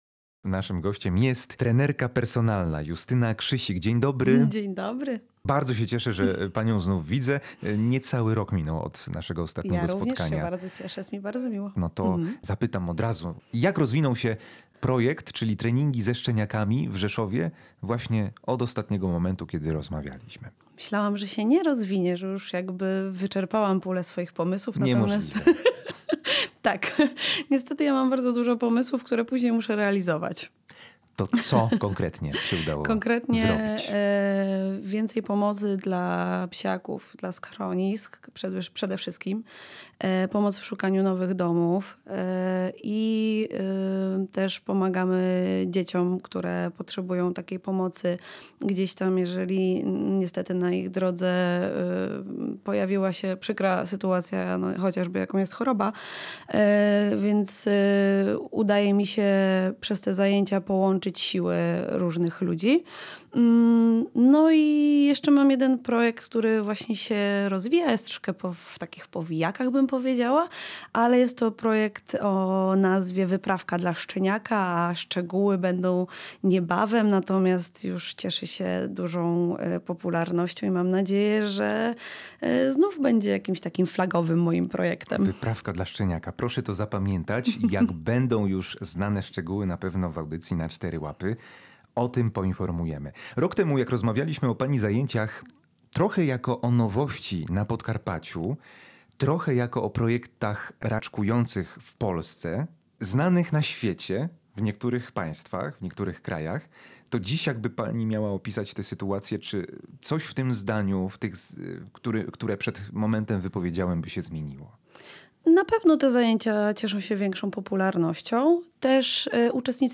Posłuchaj rozmowy w audycji „Na cztery łapy”